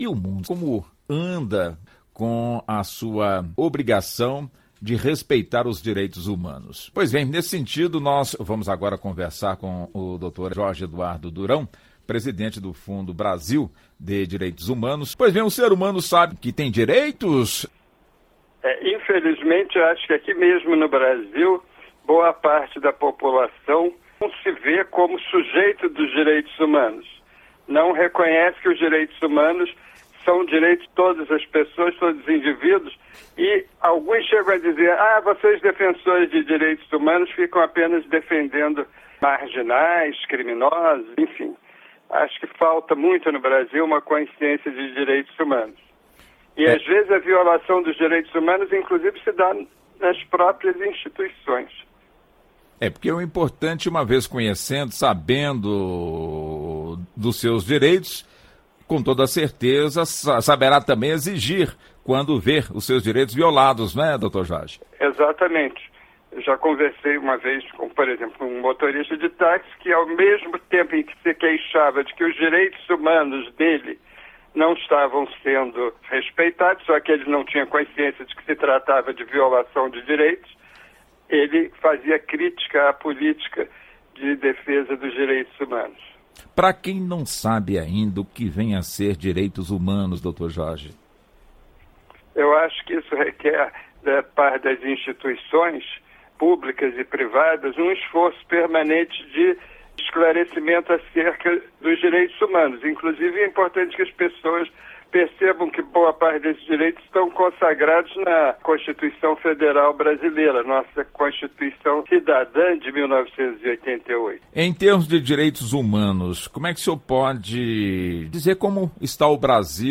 Entrevista: Especialista diz que jovens negros sofrem mais violência no país